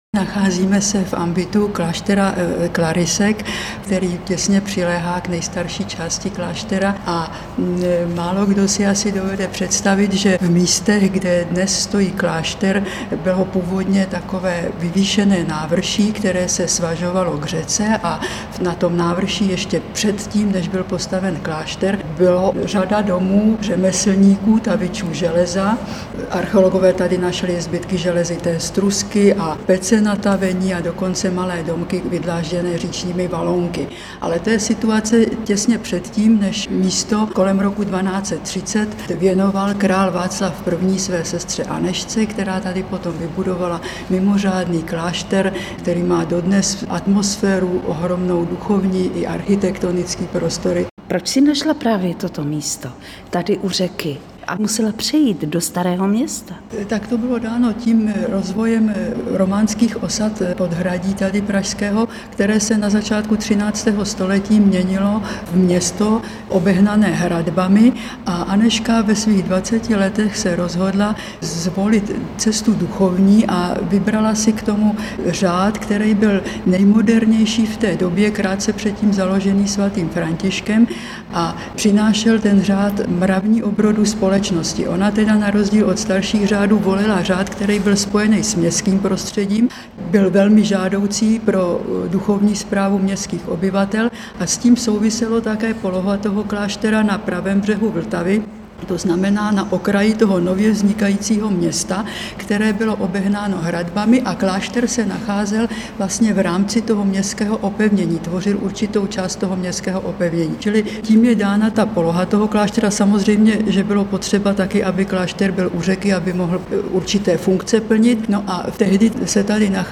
V pražském vydání Magdazínu uvádíme k zahájení Roku Anežky České reportáž z kláštera, který královská dcera z přemyslovského rodu založila pravděpodobně v roce 1231 na pravém břehu řeky Vltavy.